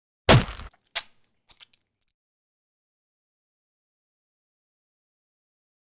赤手空拳击中肉体－高频5-YS070524.wav
通用动作/01人物/03武术动作类/空拳打斗/赤手空拳击中肉体－高频5-YS070524.wav
• 声道 立體聲 (2ch)